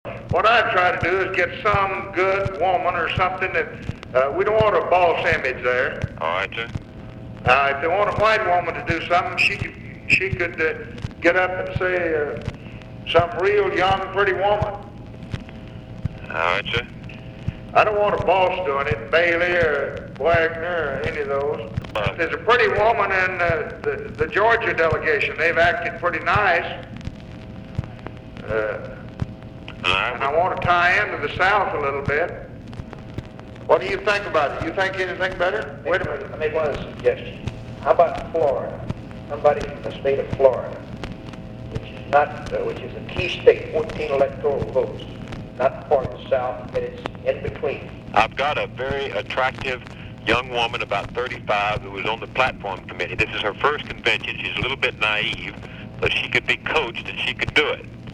He outlined his specifications in this clip with aides Bill Moyers and Jack Valenti.